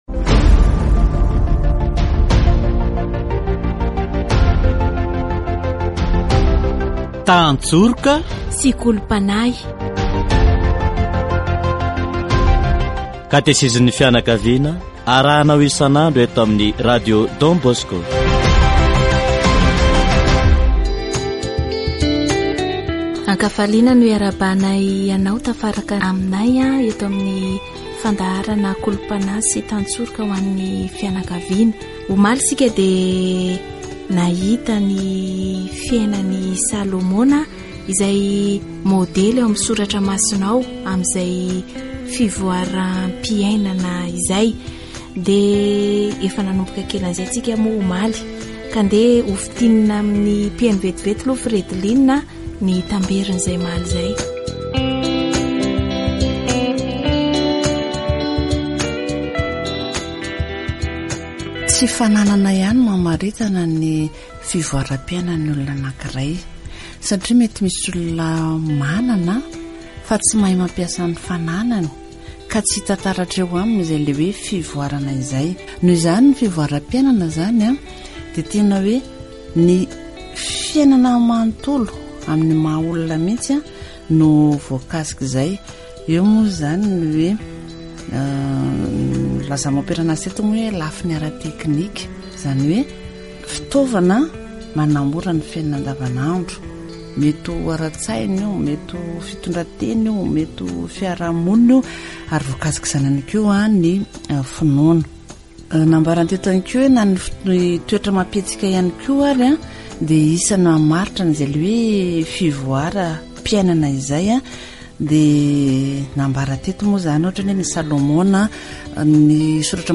Development involves the whole human being, so it involves the technical aspect, i.e. the use of tools that make daily life easier. Catechesis on the Church, the family and development